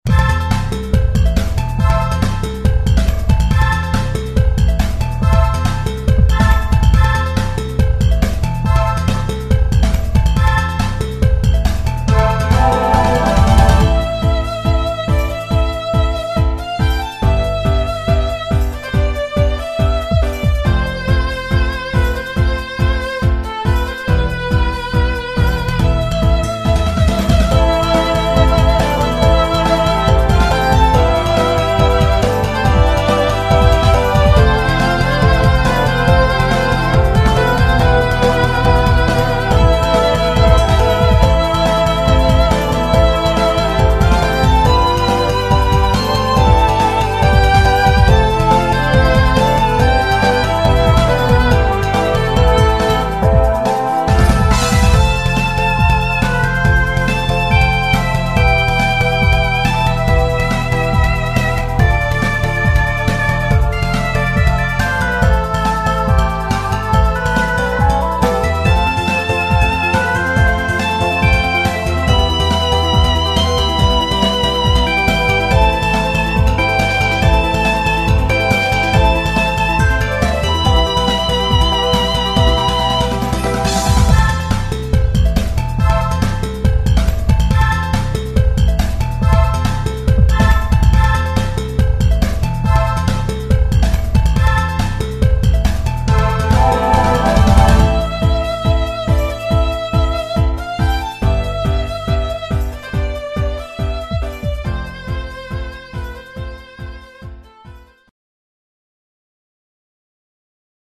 ってか、なんか某赤毛主人公の出るＡＲＰＧの某草原とか某ステージ入り口のＢＧＭっぽい(^_^;)